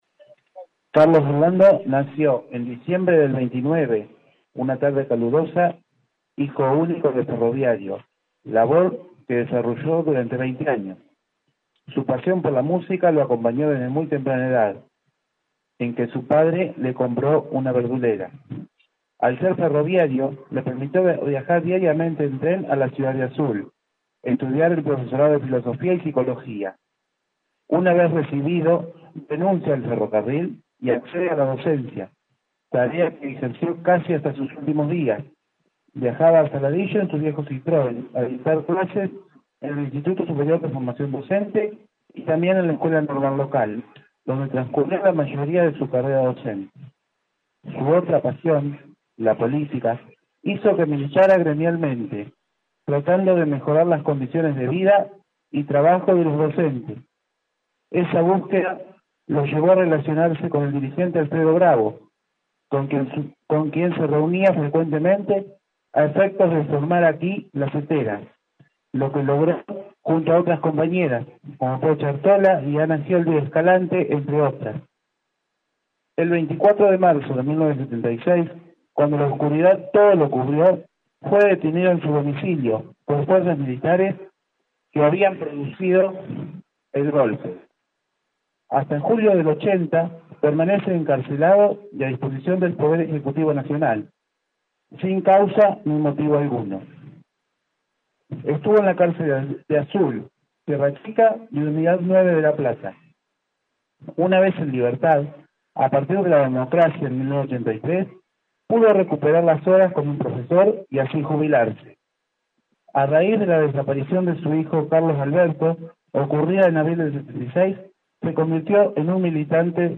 En el marco de los 40 años de la llegada de la democracia tuvo lugar en la mañana de hoy viernes el acto de imposición del nombre «Carlos Orlando Labolita» a la sede ubicada en Bdo. de Irigoyen y 25 de Mayo. Del encuentro participaron familiares del homenajeado como así también integrantes del gremio Suteba.
Acto-Suteba.mp3